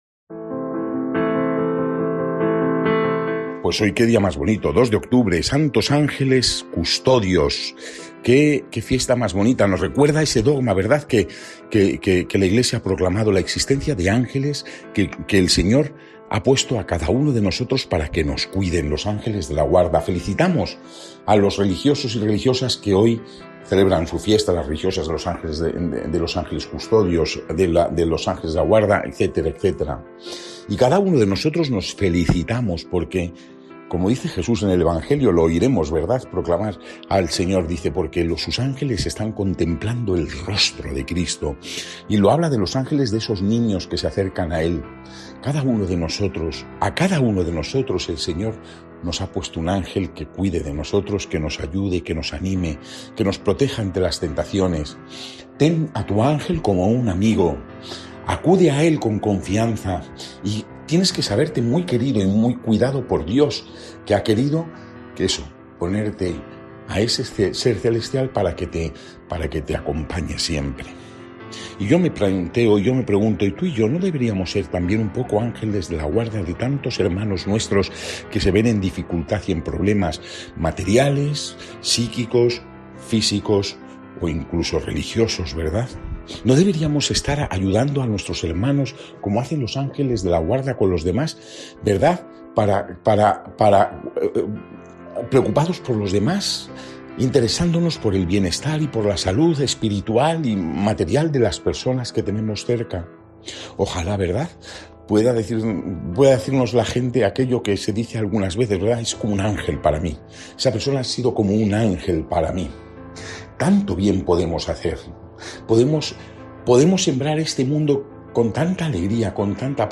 Evangelio del día